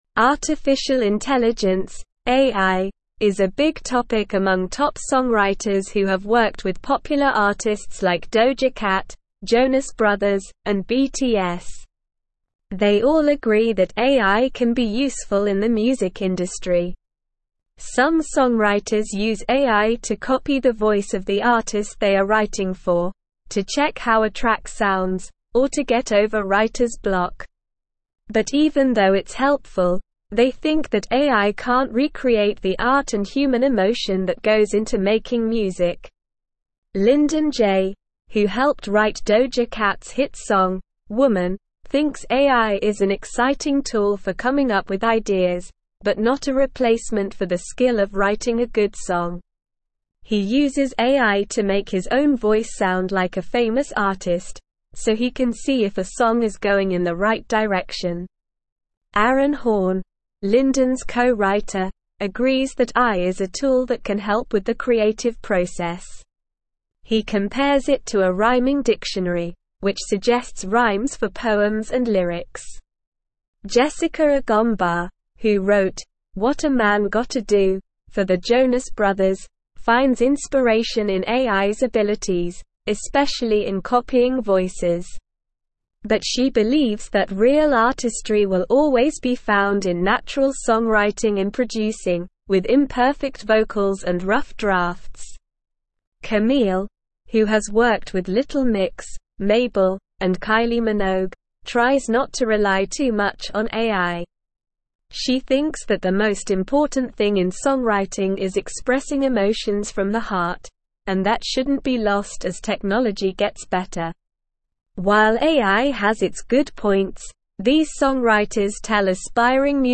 Slow
English-Newsroom-Upper-Intermediate-SLOW-Reading-Top-Songwriters-Embrace-AI-as-a-Creative-Tool.mp3